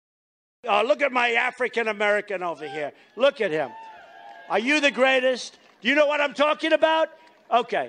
donald-trump-says-look-at-my-african-american-over-here-opieradio.mp3